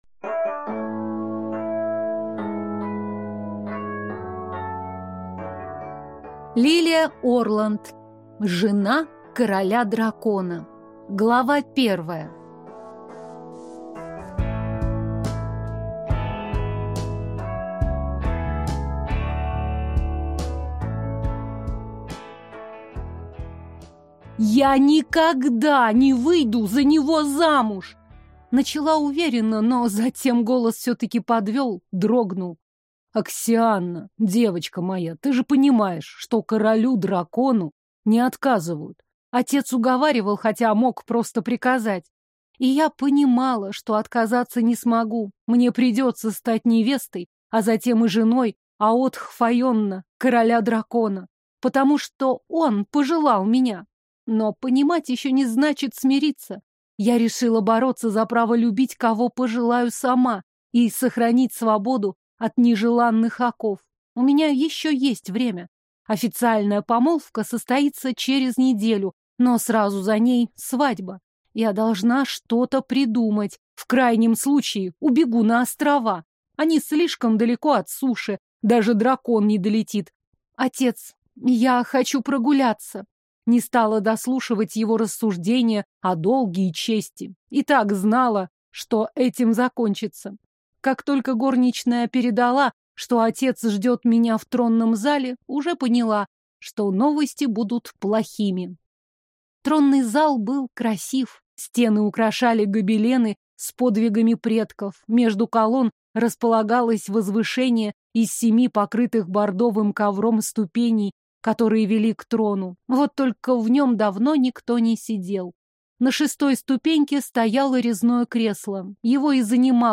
Аудиокнига Жена короля-дракона | Библиотека аудиокниг